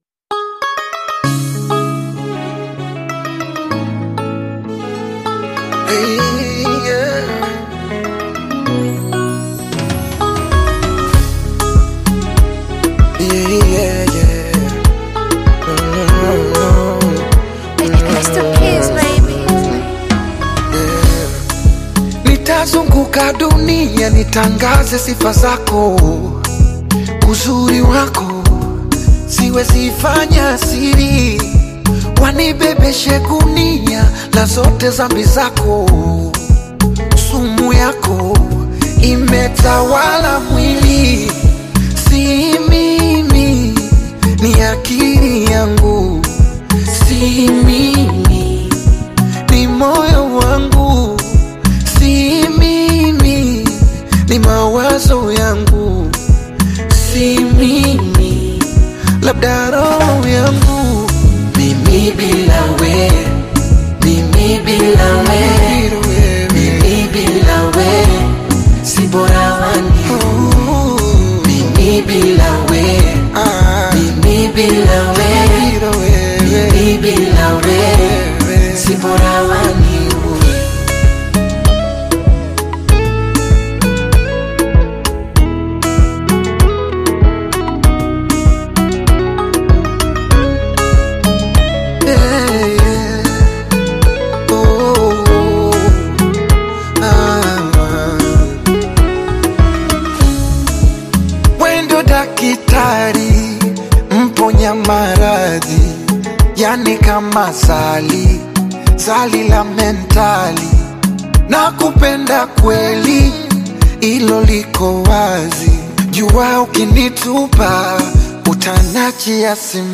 R&B with African beats